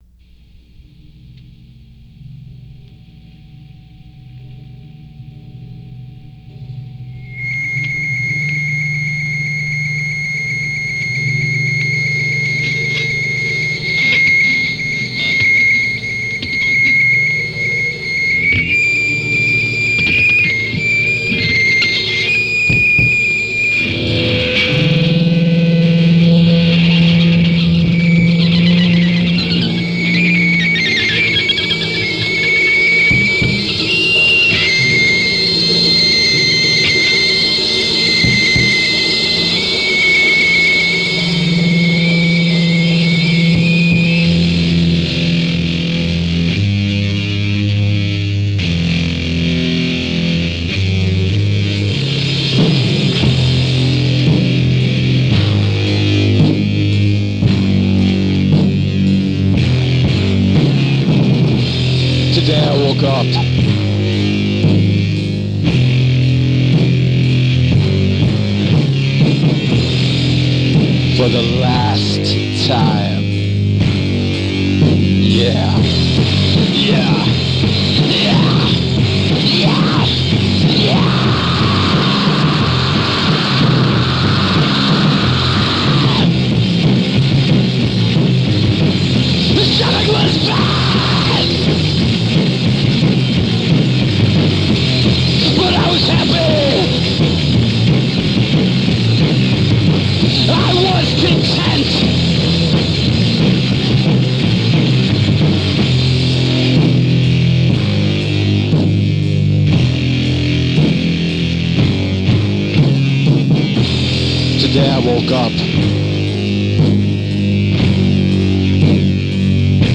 Bass / Lead Vocals
Guitar
Drums / Vox
Filed under: Punk